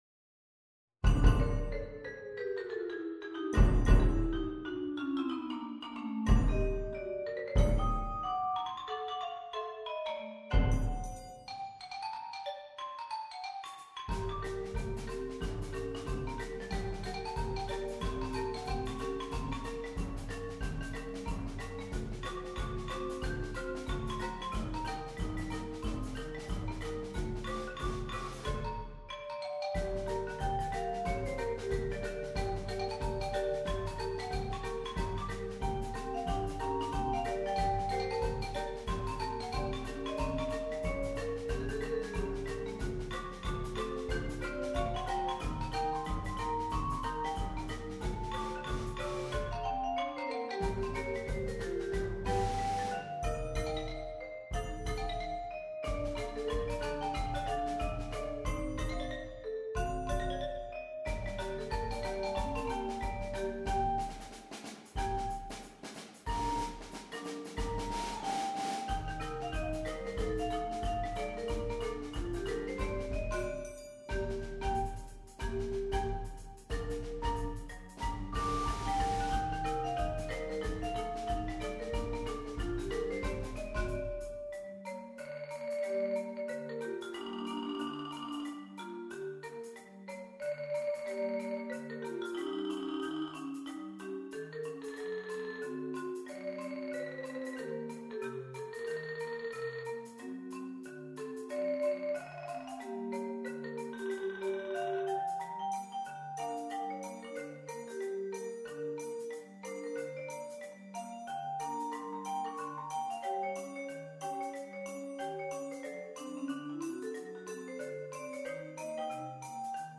Genre: Percussion Ensemble + Opt. Trombone
Xylophone, Medium Bucket
Vibraphone, Low Tin Can
Marimba 1 [4-octave], High Tin Can
Marimba 2 [4.3-octave], Low Bucket
Timpani [4 drums]
Snare Drum
Marching Bass Drum, Medium Tin Can
Washboard
Trombone [optional]